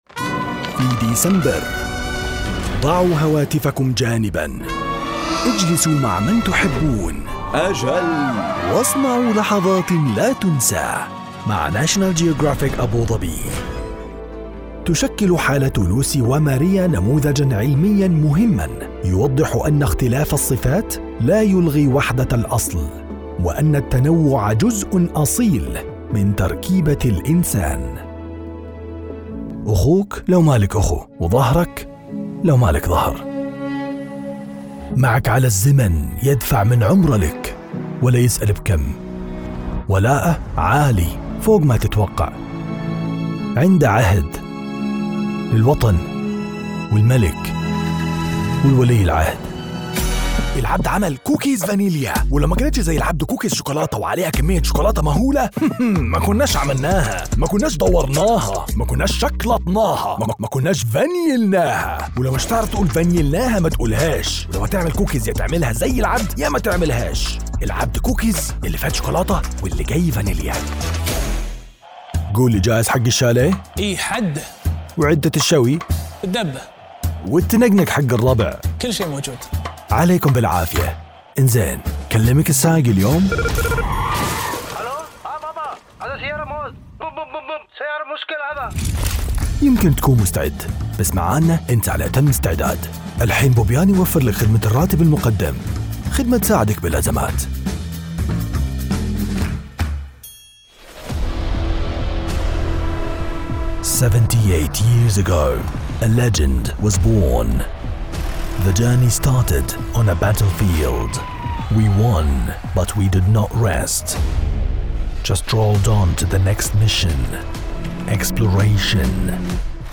Female 20s , 30s , 40s , 50s
Bright , Character , Confident , Conversational , Cool , Corporate , Engaging , Friendly , Natural , Warm , Young , Approachable , Assured , Authoritative , Bubbly , Cheeky , Energetic , Funny , Posh , Reassuring , Sarcastic , Smooth , Soft , Streetwise , Upbeat , Versatile , Wacky , Witty Animation , Audiobook , Character , Commercial , Corporate , Documentary , Educational , E-Learning , Explainer , IVR or Phone Messaging , Narration , Training , Video Game